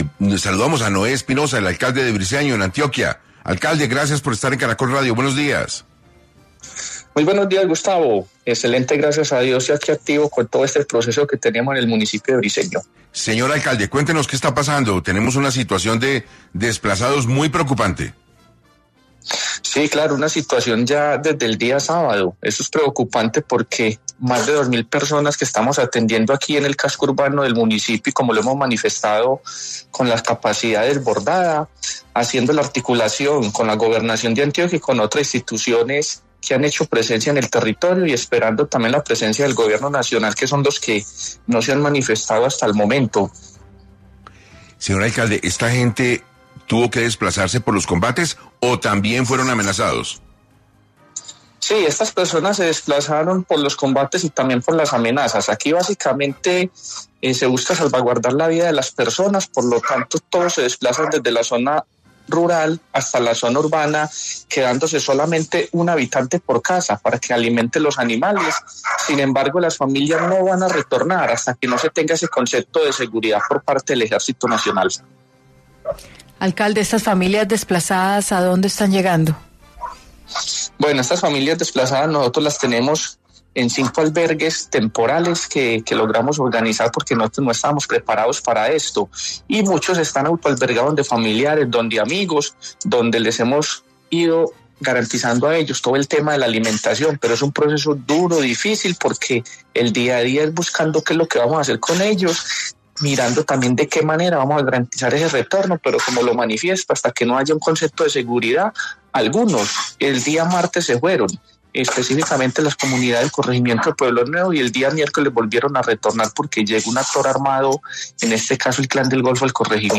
En entrevista con 6AM de Caracol Radio, Noé Espinosa, alcalde de Briceño, Antioquia, comentó que: “Tenemos una situación desde el día sábado, eso es preocupante porque son más dos mil personas que estamos atendiendo aquí en el casco urbano del municipio y estamos desbordados”.